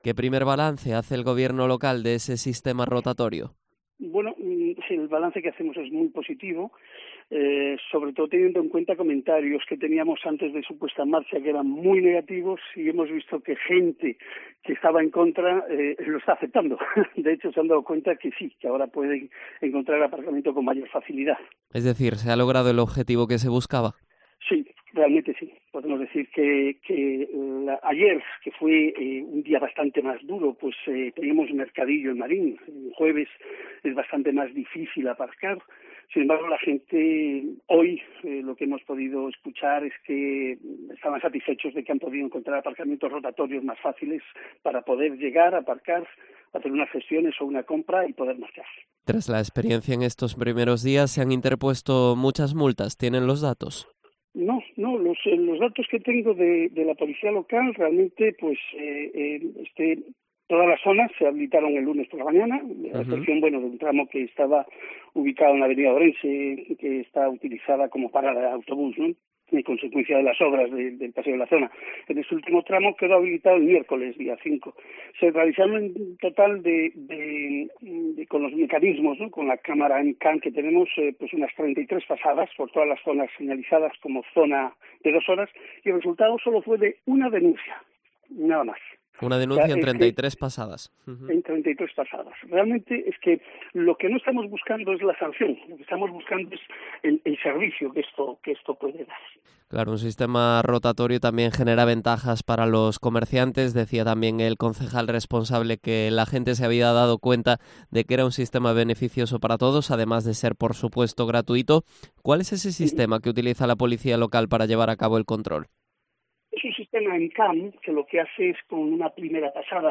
Entrevista a Francisco José Estévez, concejal de Seguridad, Policía Local y Movilidad